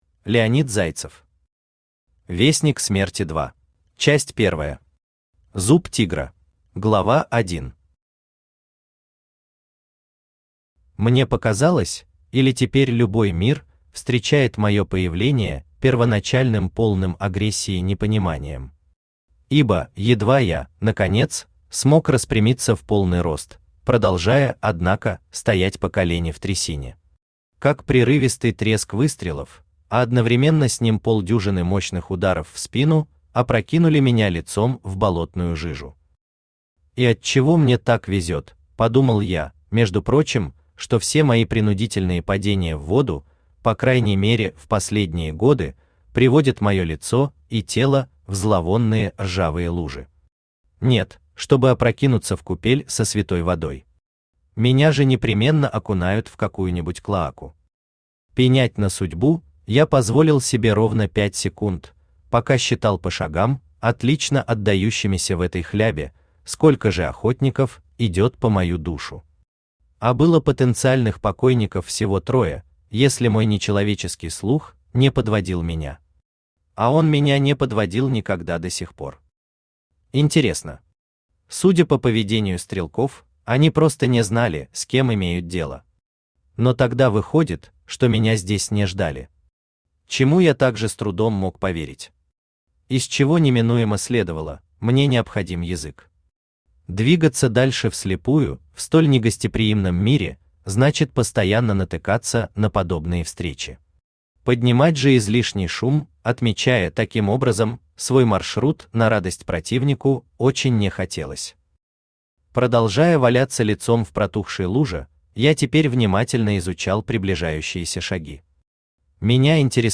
Аудиокнига Вестник смерти 2 | Библиотека аудиокниг
Aудиокнига Вестник смерти 2 Автор Леонид Зайцев Читает аудиокнигу Авточтец ЛитРес.